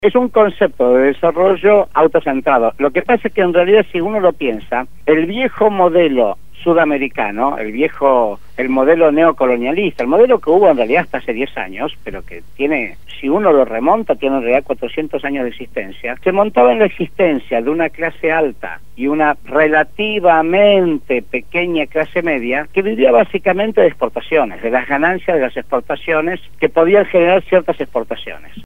en el programa Punto de Partida de Radio Gráfica FM 89.3